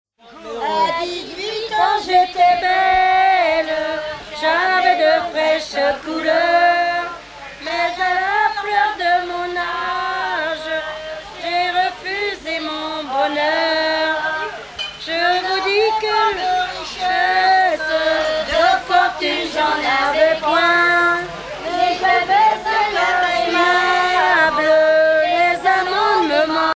Genre strophique
Chanteuse du pays de Redon